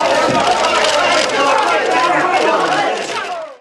Crowd Free sound effects and audio clips
• ANGRY QUESTIONING CROWD.wav
ANGRY_QUESTIONING_CROWD_hIo.wav